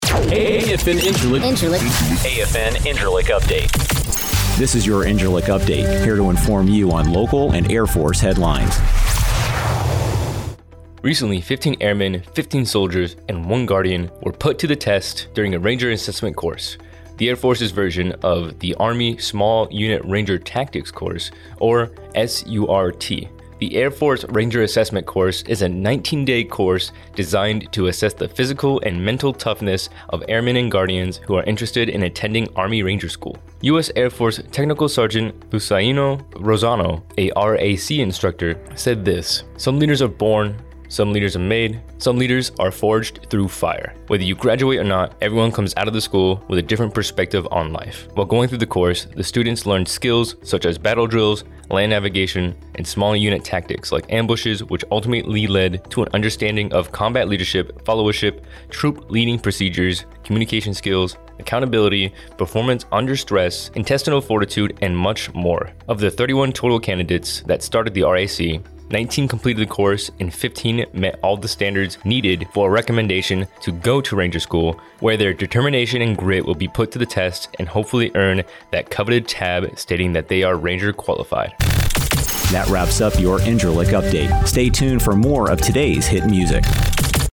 AFN INCIRLIK RADIO NEWSCAST: Ranger Assessment Course